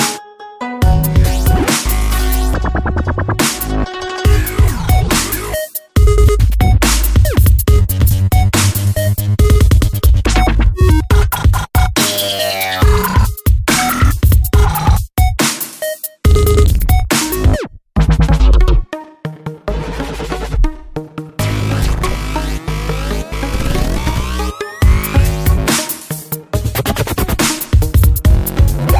• Качество: 128, Stereo
СМС с красивой и чёткой мелодией!